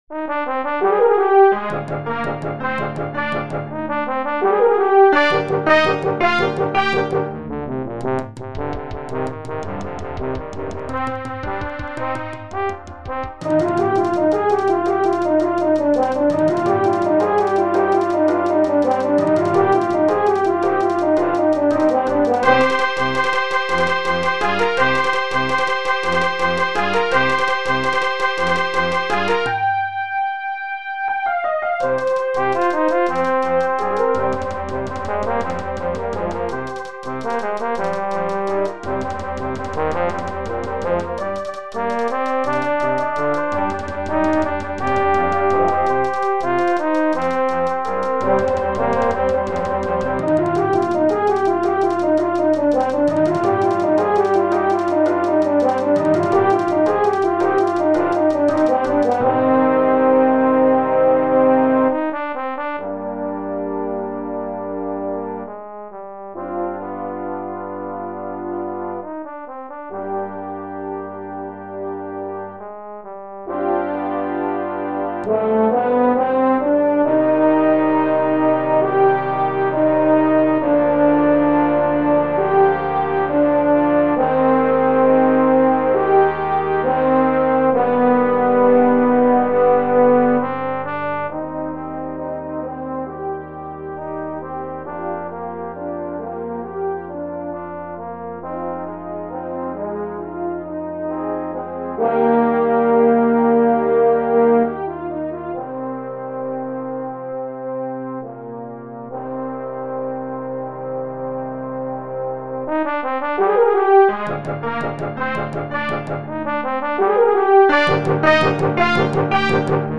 een Spaansachtige dans
Bezetting : Brassband